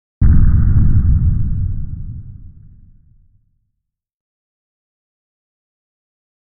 Подводный запуск торпеды с корабля